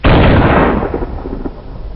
FIGHT-Explosion+5
Tags: combat